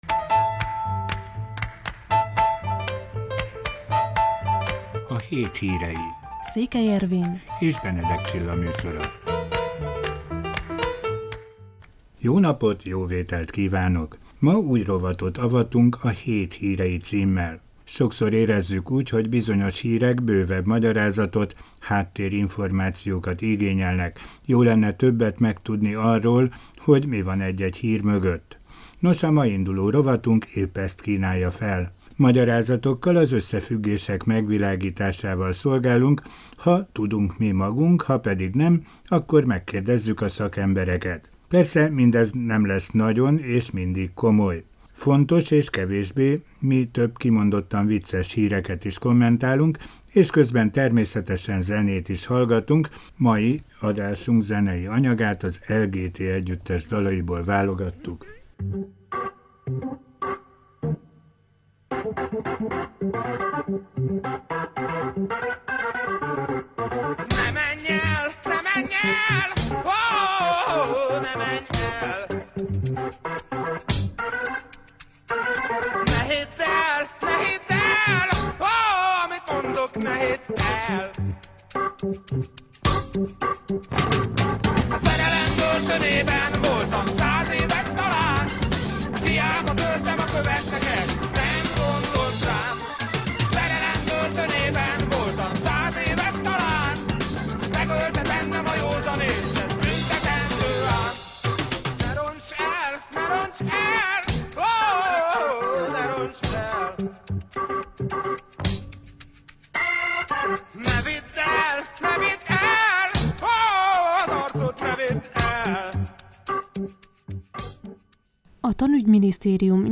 Fontos és kevésbé fontos, mi több kimondottan vicces híreket is kommentálunk és közben természetesen zenét is hallgatunk.